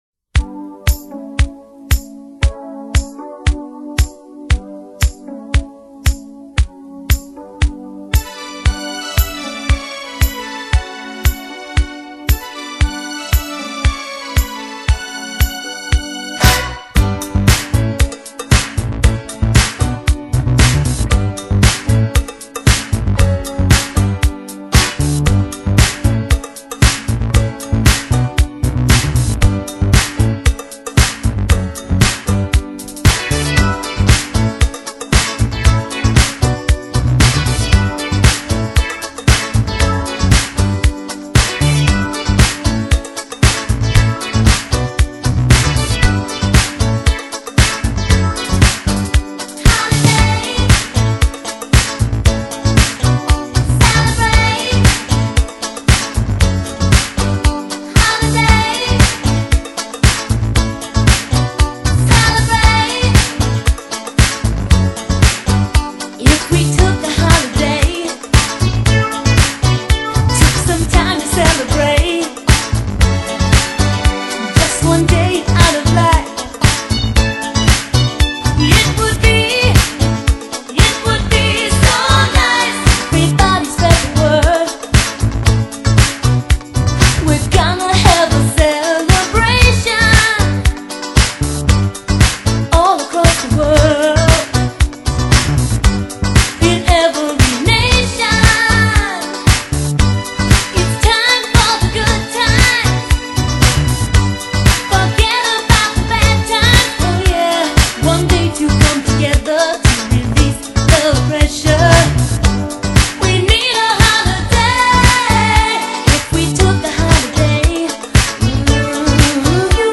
Pop, Dance-Pop